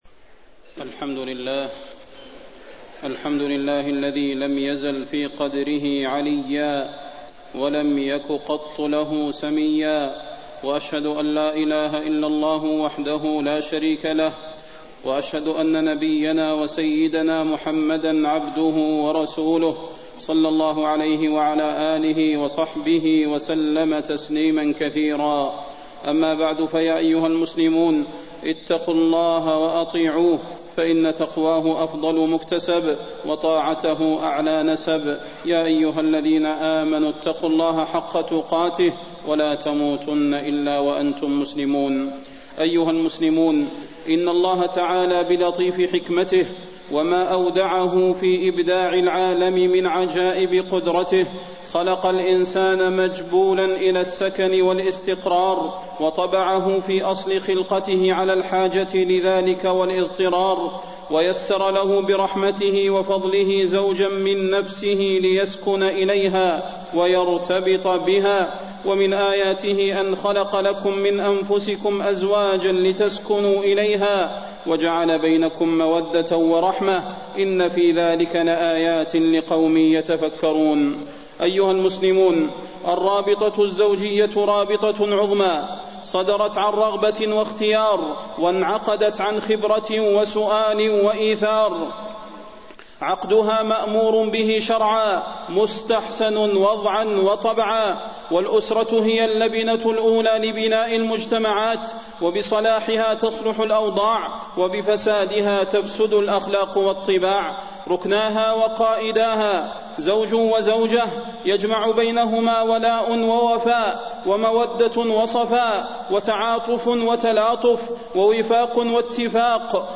فضيلة الشيخ د. صلاح بن محمد البدير
تاريخ النشر ٣ ربيع الثاني ١٤٣١ هـ المكان: المسجد النبوي الشيخ: فضيلة الشيخ د. صلاح بن محمد البدير فضيلة الشيخ د. صلاح بن محمد البدير نصائح للزوجين